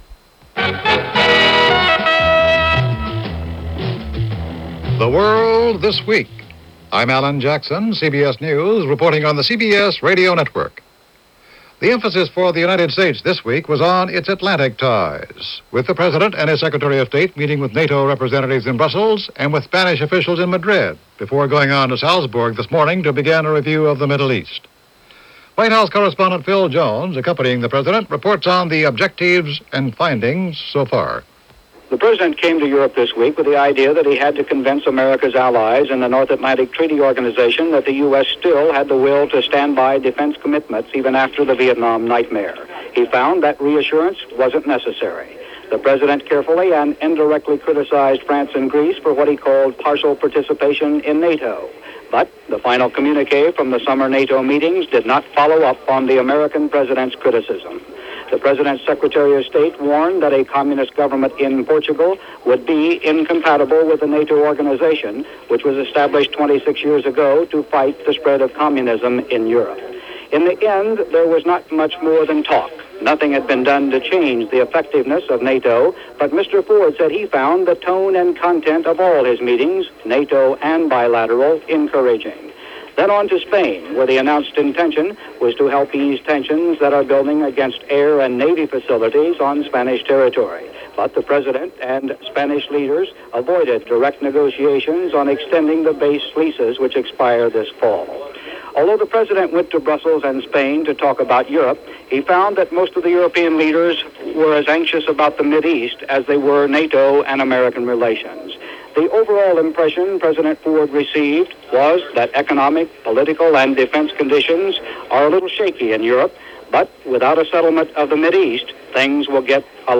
And that was just a small slice of what happened this June 1st in 1975 as reported on The World This Week from CBS Radio.